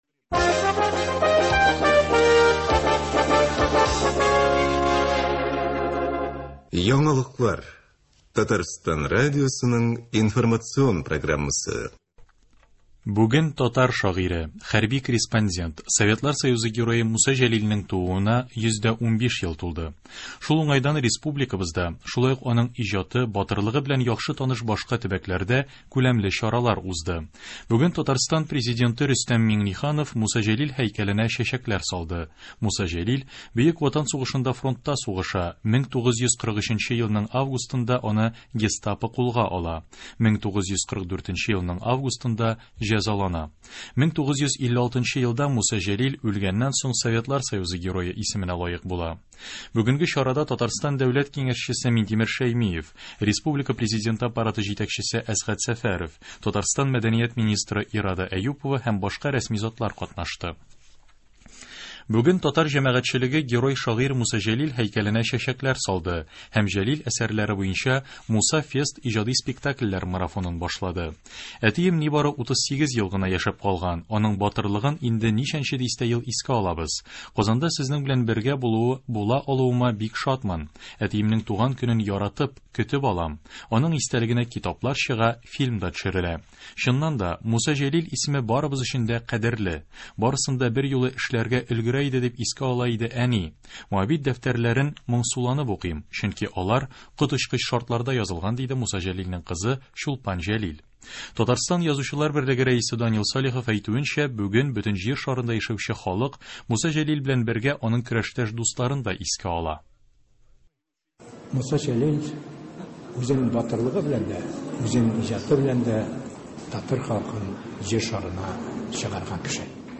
Яңалыклар (15.02.21)